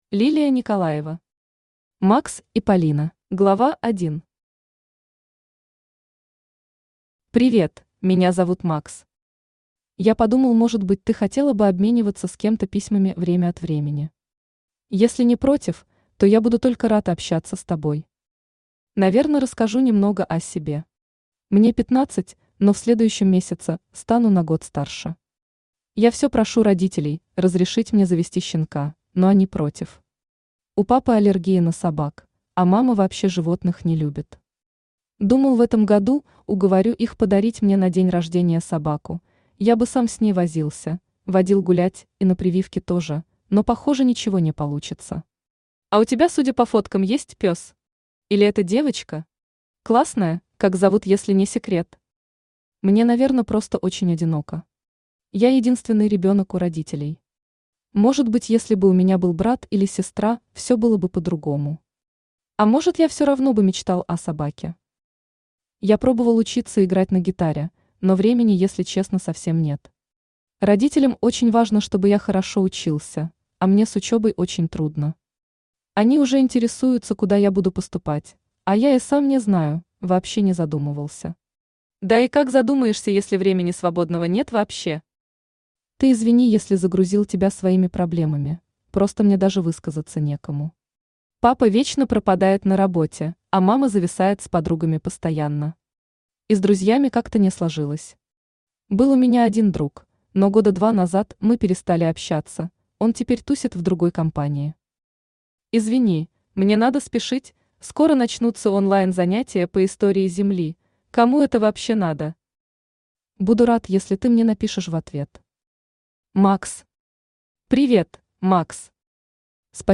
Аудиокнига Макс и Полина | Библиотека аудиокниг
Aудиокнига Макс и Полина Автор Лилия Николаева Читает аудиокнигу Авточтец ЛитРес.